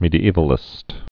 (mēdē-ēvə-lĭst, mĕdē-)